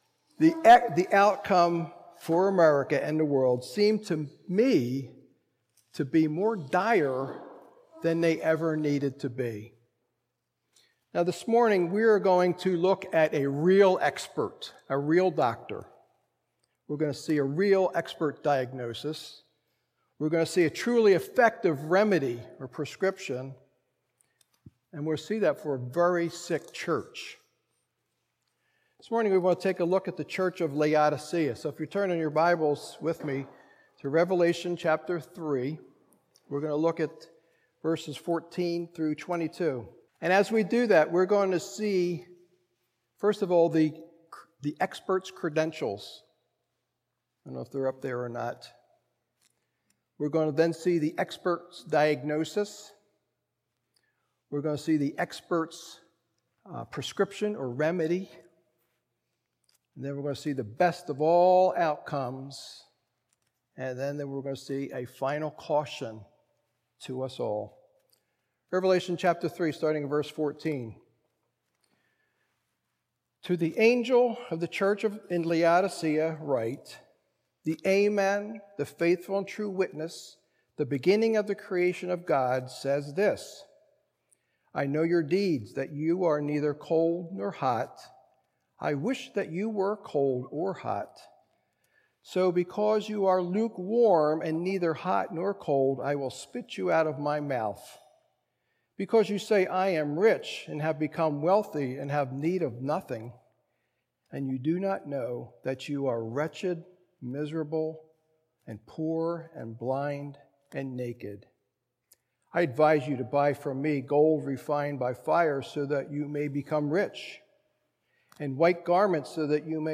Good Friday service. 2024